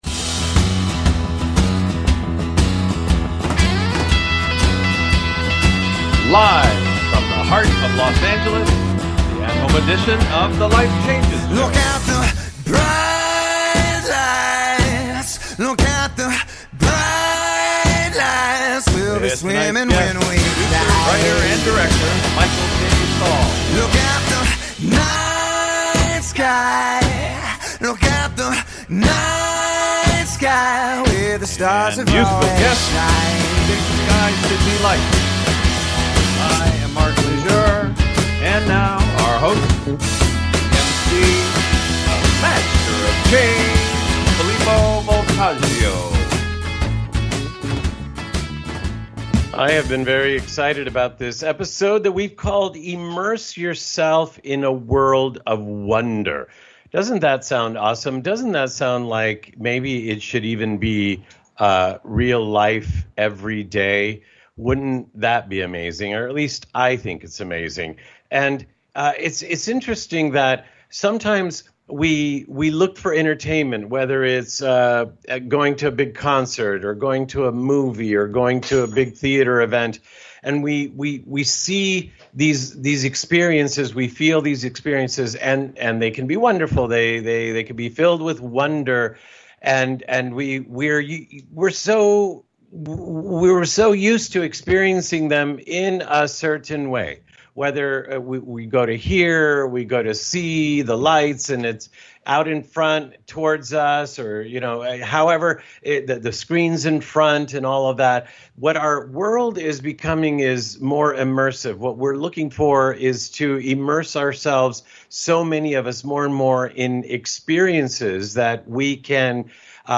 Talk Show Episode
and Musical Guests, Indie Folk Duo, Big Sky City Lights
and Musical Guests: BIG SKY CITY LIGHTS w